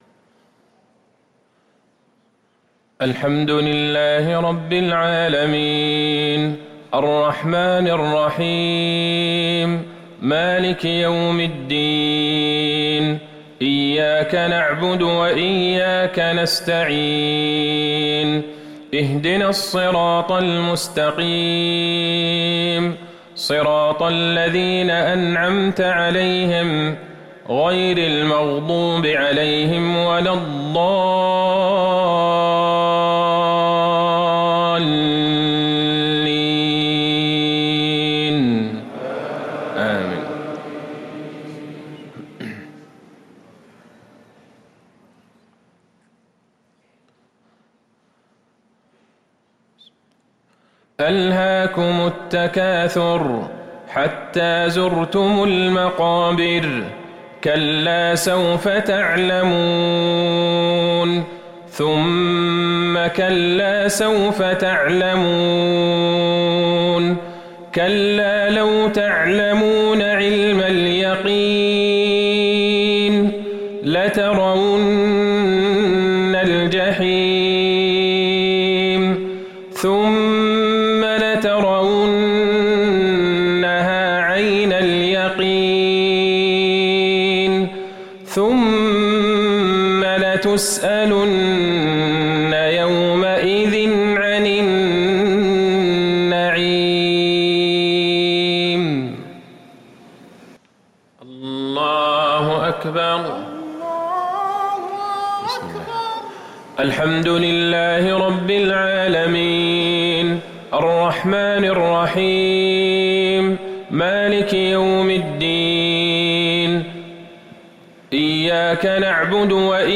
مغرب الأثنين 9-6-1444هـ سورتي التكاثر و العصر | Maghrib prayer from Surah at-Takathur and Al-A’asr 2-1-2023 > 1444 🕌 > الفروض - تلاوات الحرمين